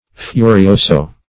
furioso - definition of furioso - synonyms, pronunciation, spelling from Free Dictionary Search Result for " furioso" : The Collaborative International Dictionary of English v.0.48: Furioso \Fu"ri*o"so\, a. & adv.